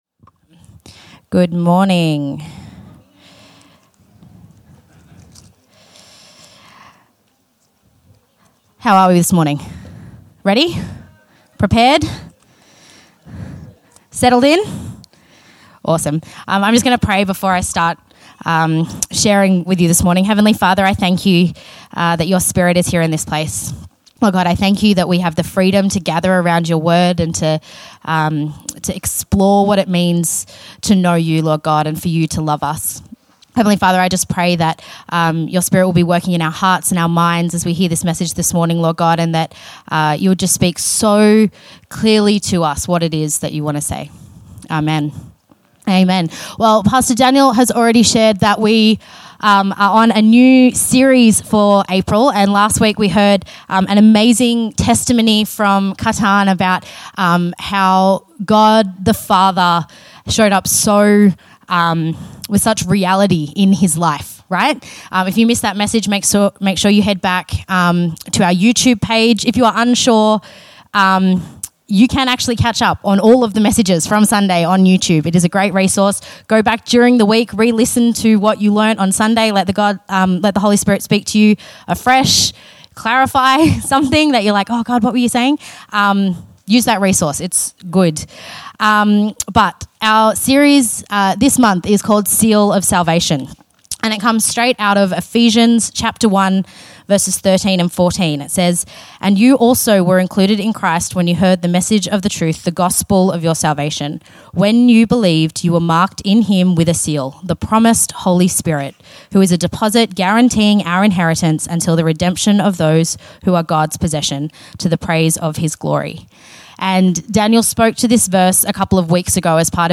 The Infinity Church Podcast - English Service | Infinity Church
Current Sermon